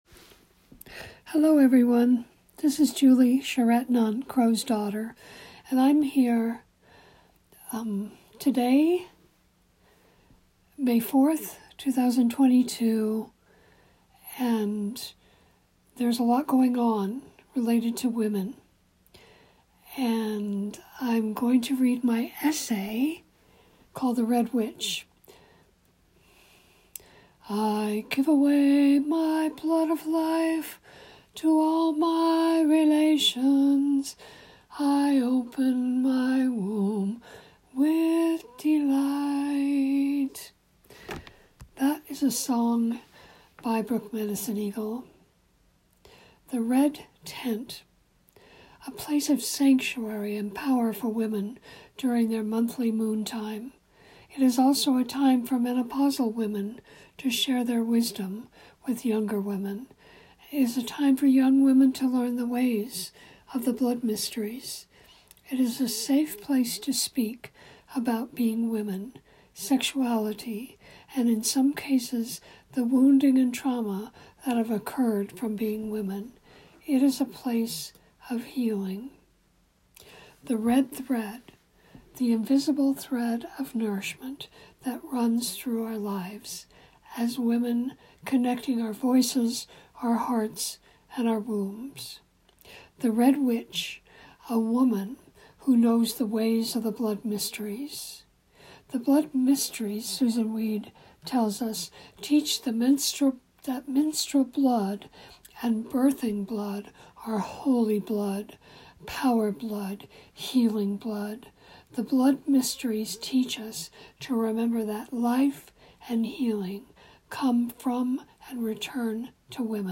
I just made a recording of this essay.